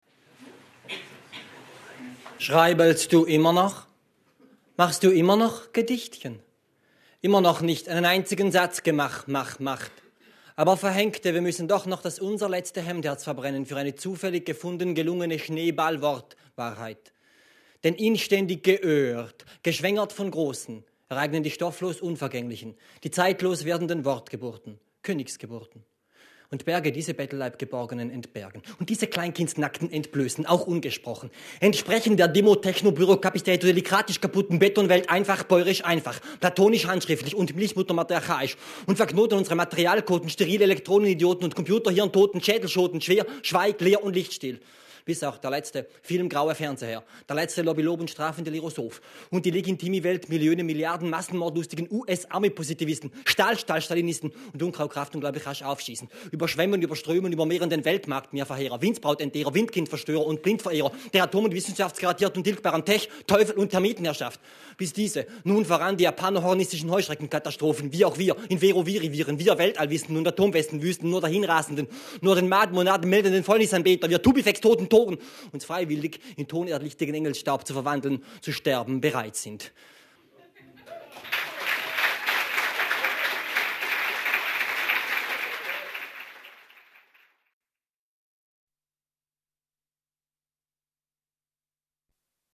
Lesung
in der literaturWERKstatt Berlin zur Sommernacht der Lyrik – Gedichte von heute